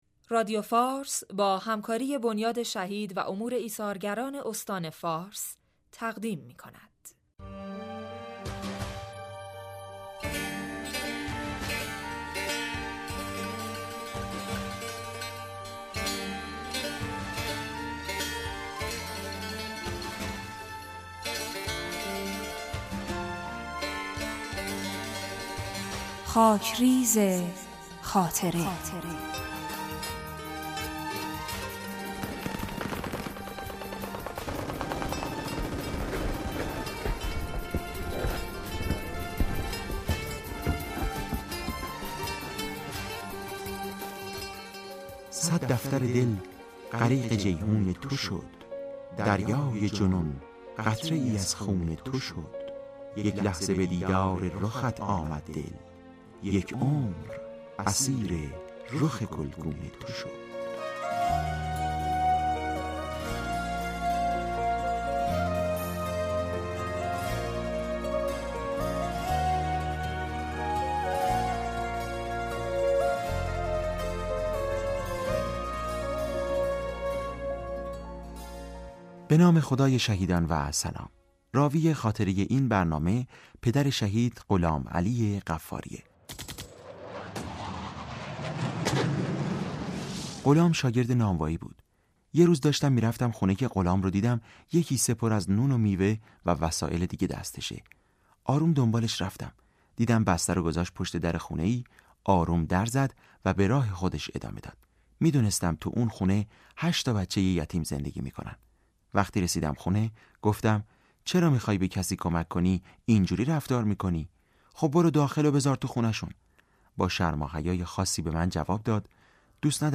صوت / خاطره ای از شهيد